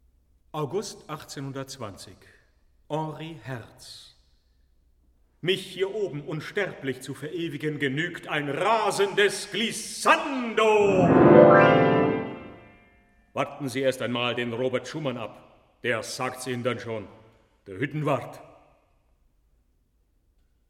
in the style of the old masters